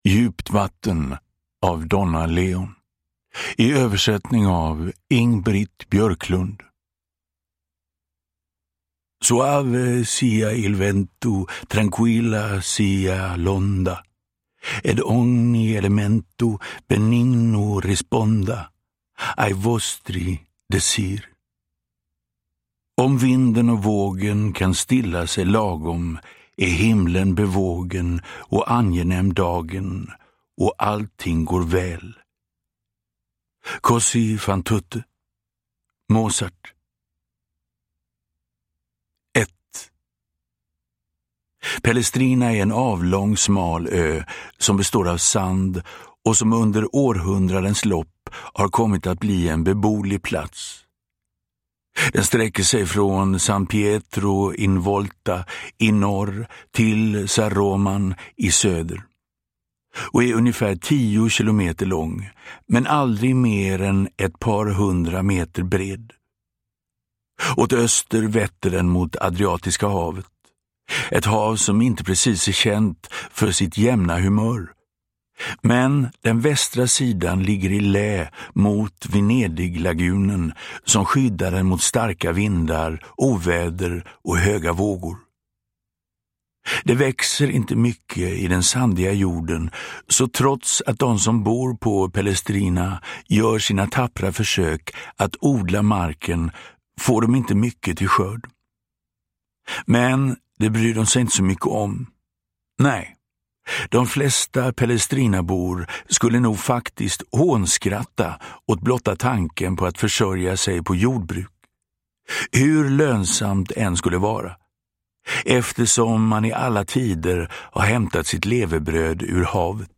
Djupt vatten – Ljudbok – Laddas ner
Uppläsare: Magnus Roosmann